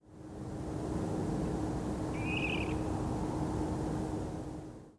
Water Rail (Rallus aquaticus)
1 – Trill
This call can be quite explosive with usually a strong burry trill. The call is usually arched, which can be heard as a rising and falling within the call, and makes it easy to separate from superficially similar Dunlin calls (which just descend in frequency). The amount of modulation varies.
Water Rail trill
clip_wa_trill.mp3